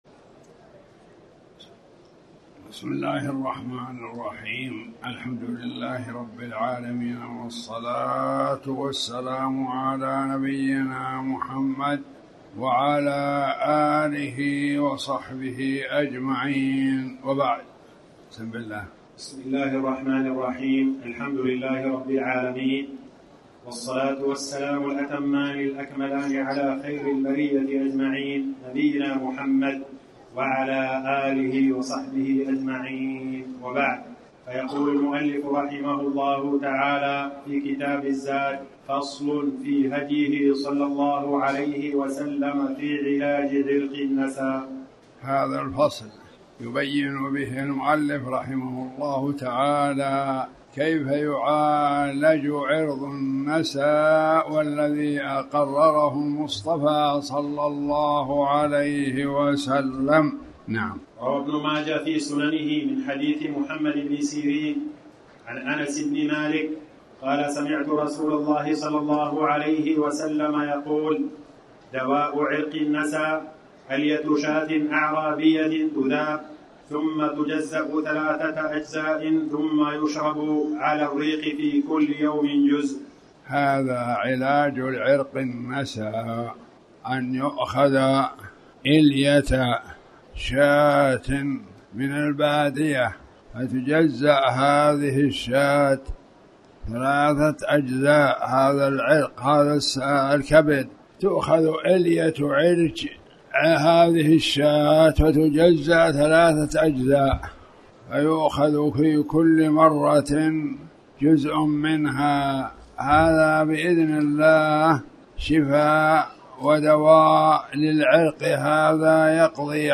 تاريخ النشر ١١ ربيع الثاني ١٤٣٩ هـ المكان: المسجد الحرام الشيخ